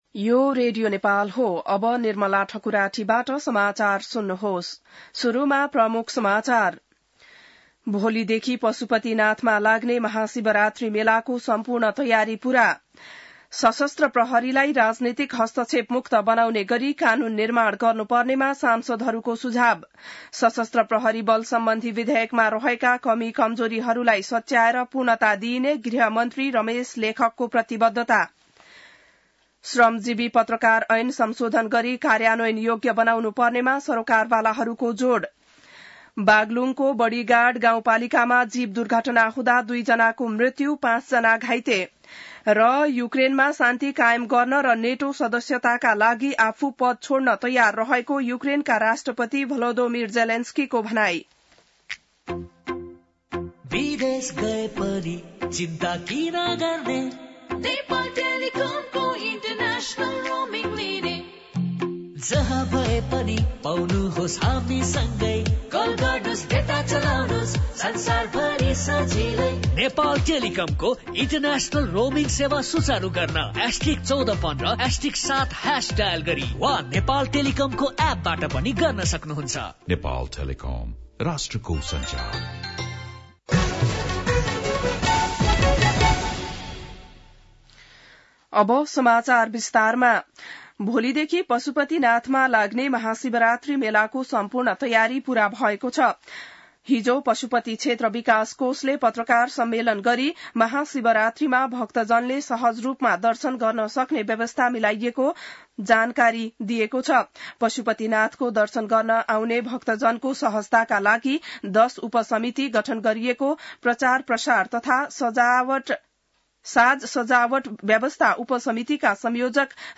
बिहान ७ बजेको नेपाली समाचार : १४ फागुन , २०८१